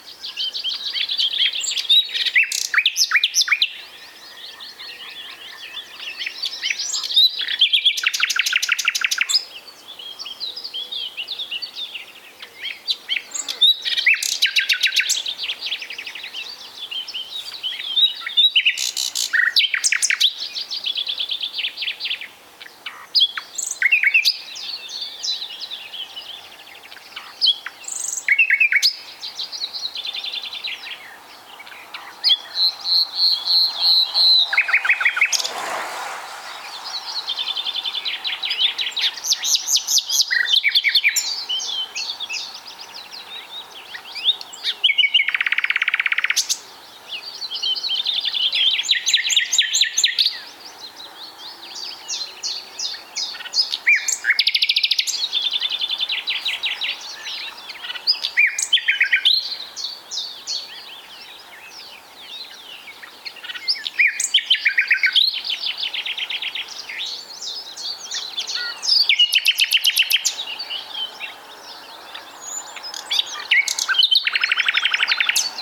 Privighetoarea roşcată - Luscinia megarhynchos
Privighetoarea-3-Luscinia-megarhynchos.mp3